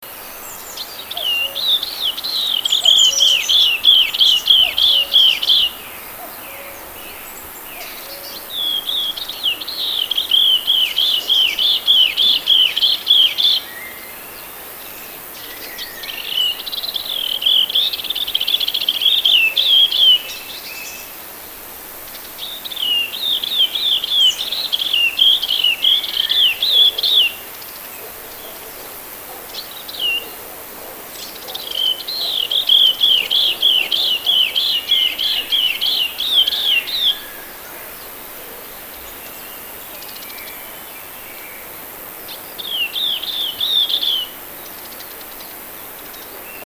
So we went out to make some sound recordings for documentation purposes.
070624, Garden Warbler Sylvia borin, atypical song, Torgau, Germany
sylvia-borin_atypical-song.mp3